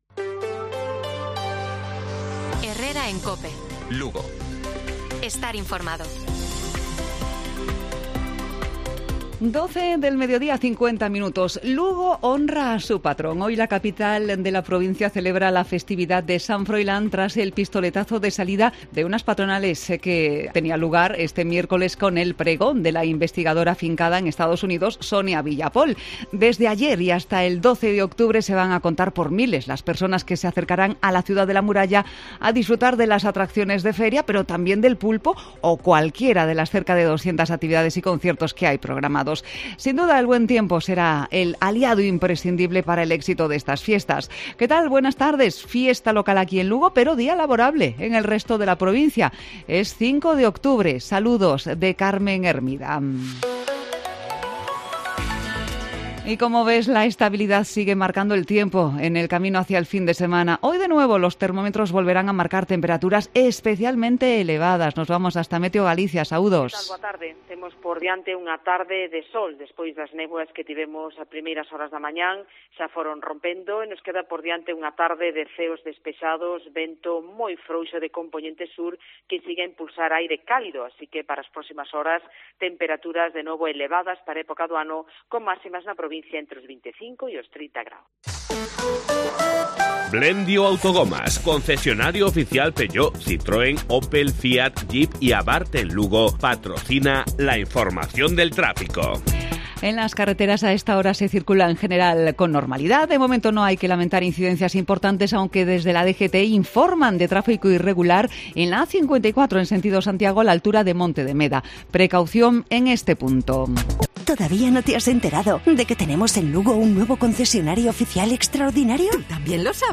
Informativo Provincial de Cope Lugo. Jueves, 5 de octubre. 12:50 horas